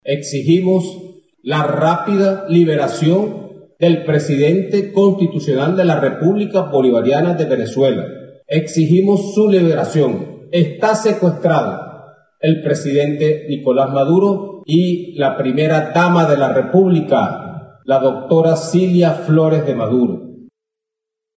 El ministro de Defensa, Vladimir Padrino López, anunció que será ella quien garantice la gobernabilidad y exigió la “rápida liberación” de Maduro.